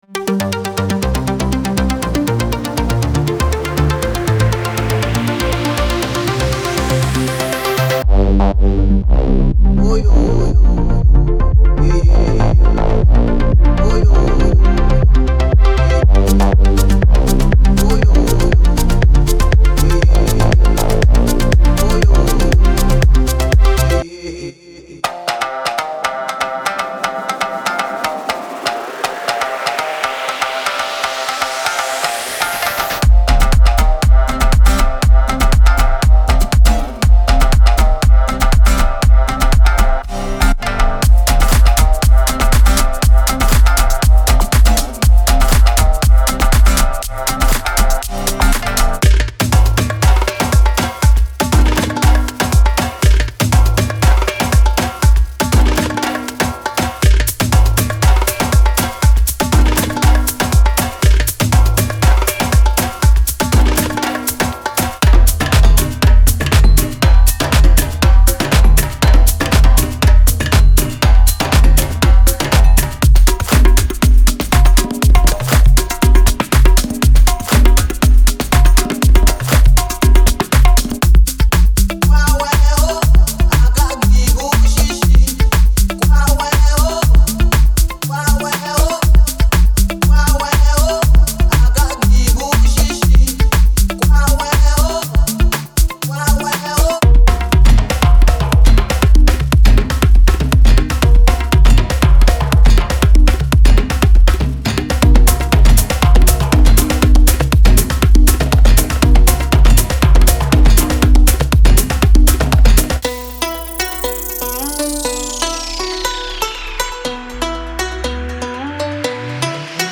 Genre:House
デモサウンドはコチラ↓
130 Drum Loops
30 Percussion Loops
26 Vocal Loops